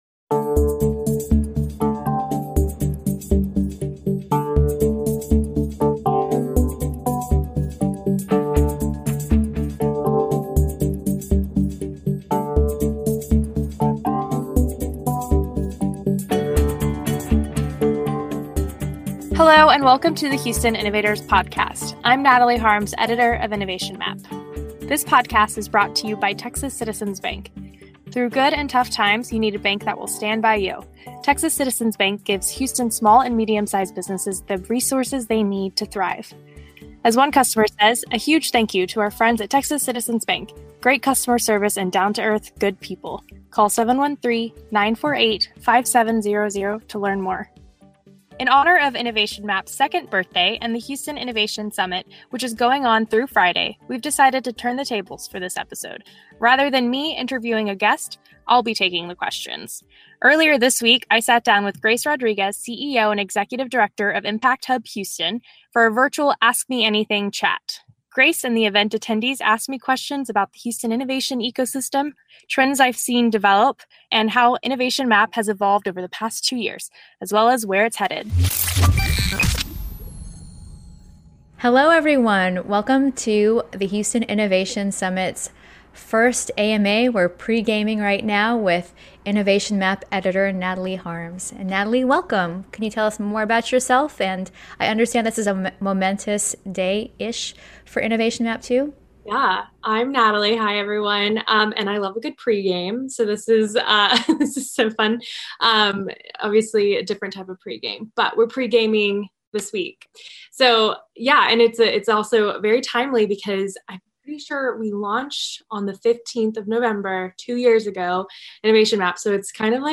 for a virtual fireside chat.